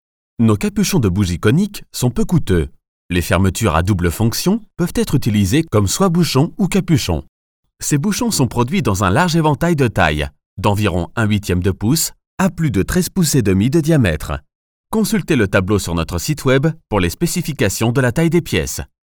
He owns his home studio with ISDN and Neumann microphone His voice and studio are accredited by SaVoa (Society of Accredited Voice Over Artists). His style can be : convincing, reassuring, dynamic, soft, sensitive, elegant, Warm.
Sprechprobe: Industrie (Muttersprache):